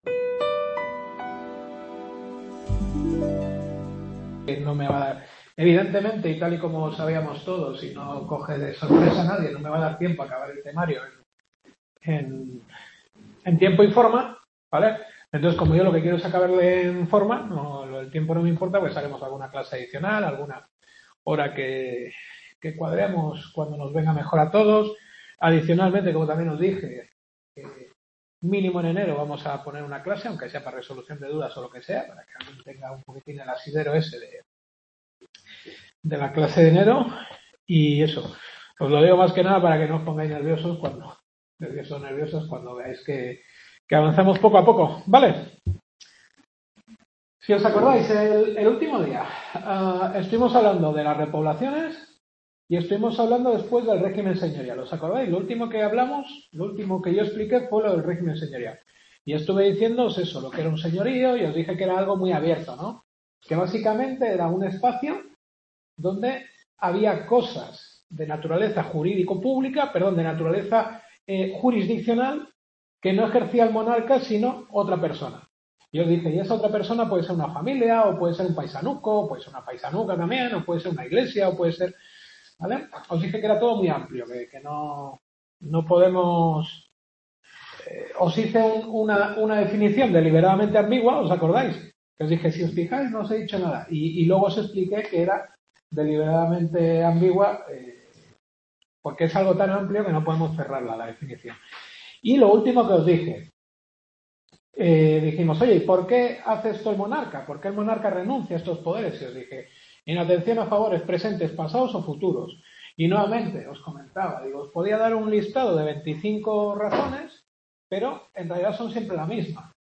Historia del Derecho. Octava Clase.